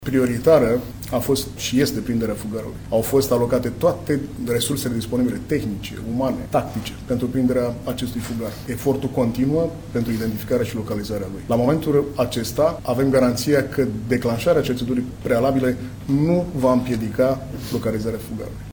Șeful Poliției Române, Benone Matei: „Au fost alocate toate resursele disponibile: tehnice, tactice, umane, pentru prinderea acestui fugar”